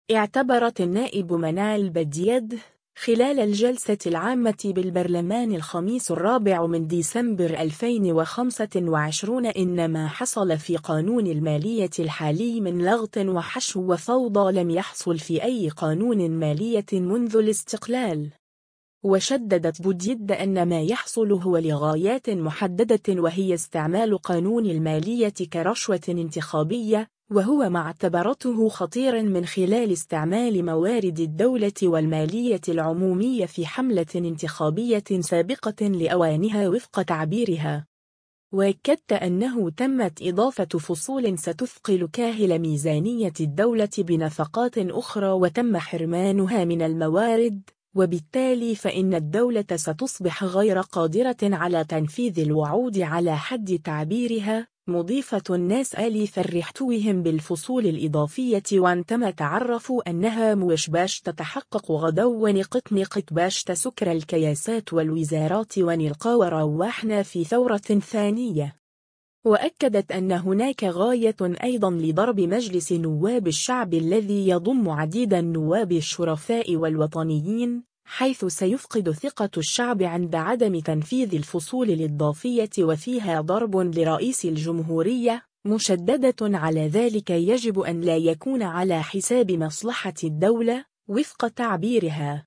اعتبرت النائب منال بديدة، خلال الجلسة العامة بالبرلمان الخميس 4 ديسمبر 2025 ان ما حصل في قانون المالية الحالي من لغط وحشو وفوضى لم يحصل في أي قانون مالية منذ الاستقلال.